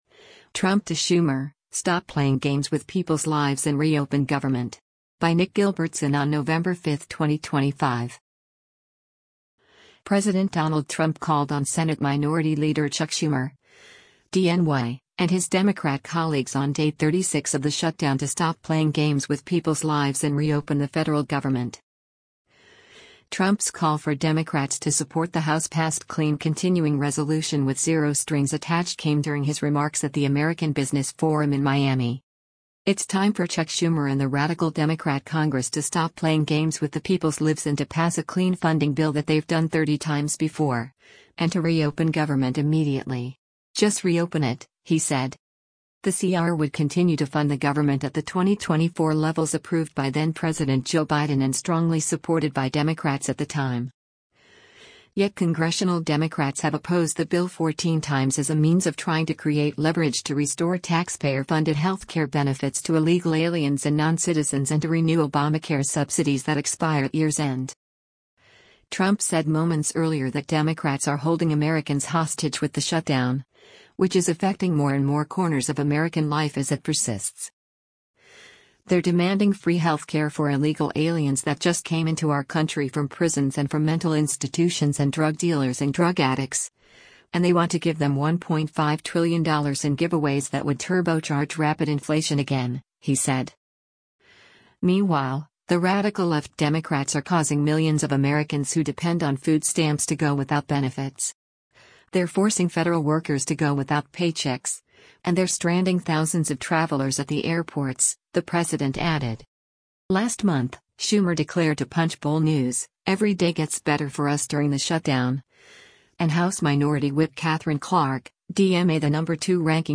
Trump’s call for Democrats to support the House-passed clean Continuing Resolution–with zero strings attached–came during his remarks at the American Business Forum in Miami.